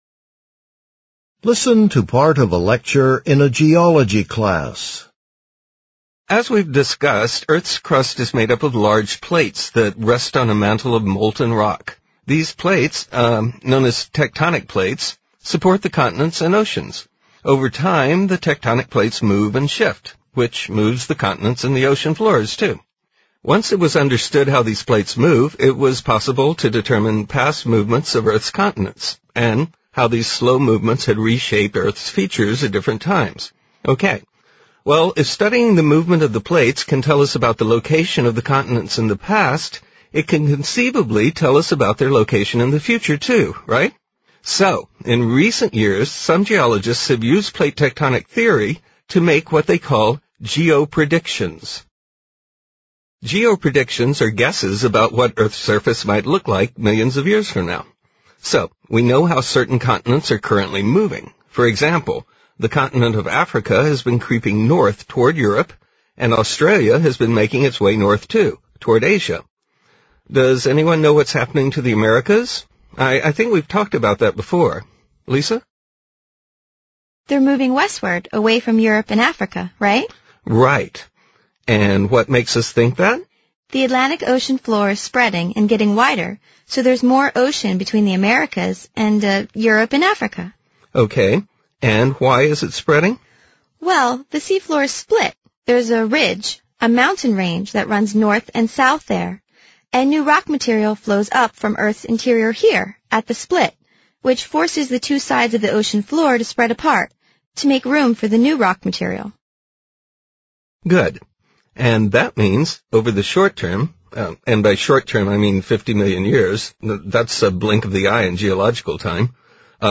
What is the lecture mainly about?